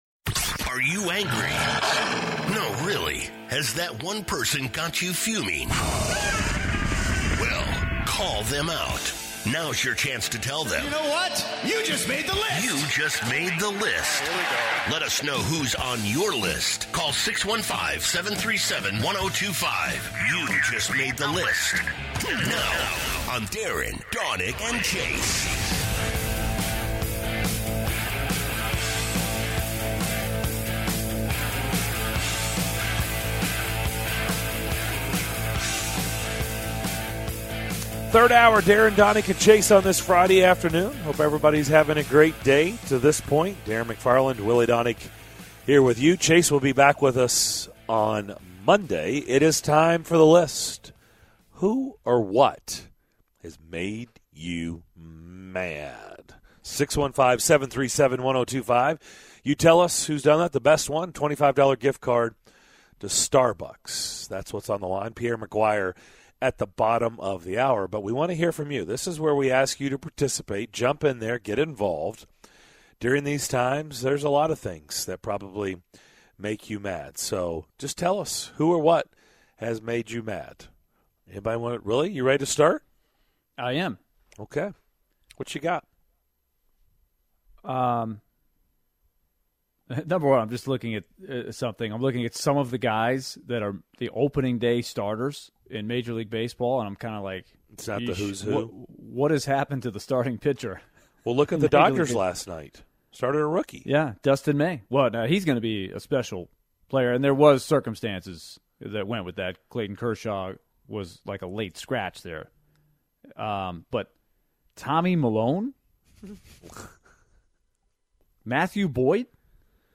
This hour begins with "You Just Made The List" where the audience has a chance to put someone or something on the list. Pierre McGuire from NHL on NBC joins the show to talk some Preds and NHL return. What will the bubble city life be like in Edmonton?